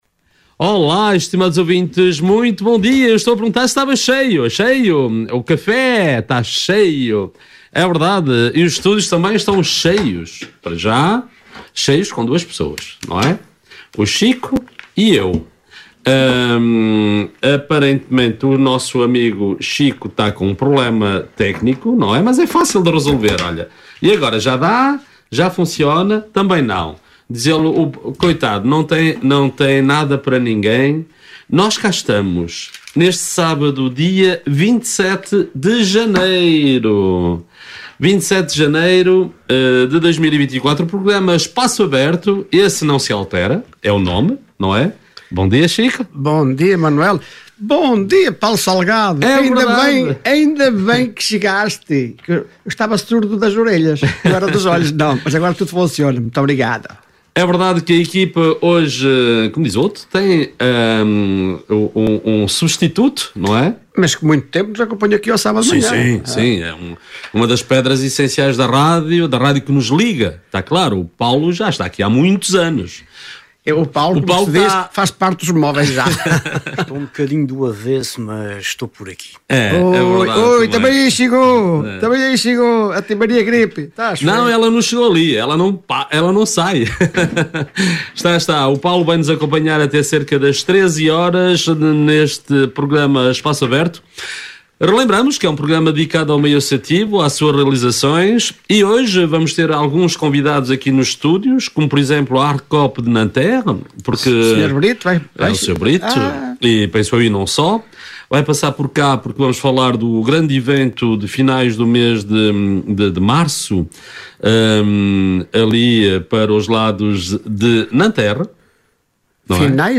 Todas as semanas a equipa do Espaço Aberto recebe em estúdio as associações lusófonas para a divulgação das atividades associativas.